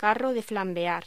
Locución: Carro de flambear